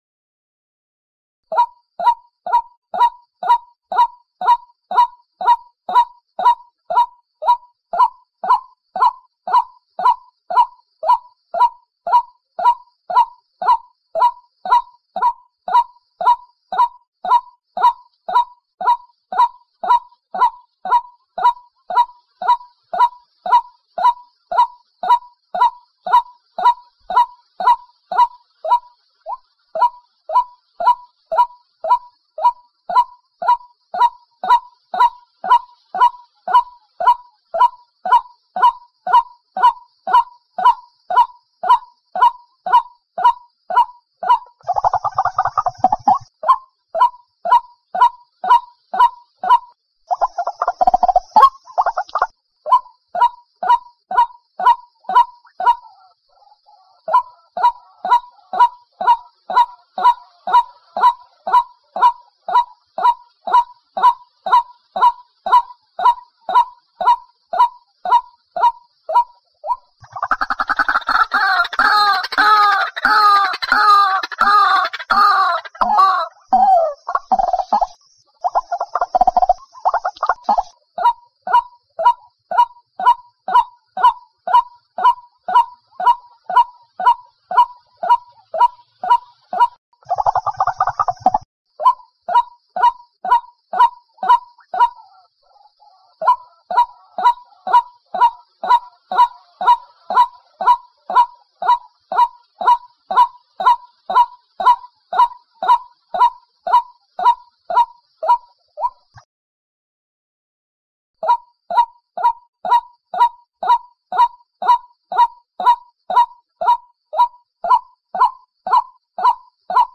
Suara burung Ruak Ruak jantan
Tag: suara Kareo Padi/ Ruak-ruak suara ladang/ rawa